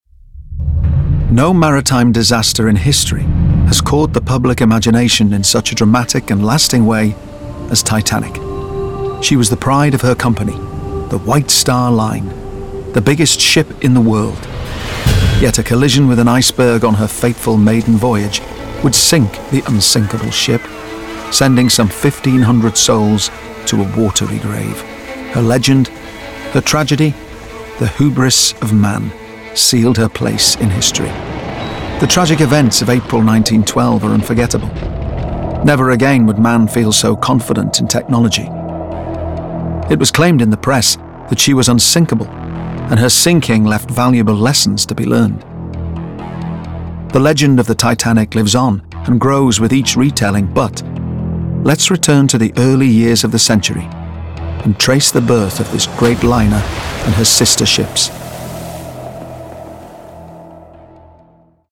Narration Showreel
Male
Liverpool
Northern
Friendly
Down To Earth
Informative
Reassuring
Warm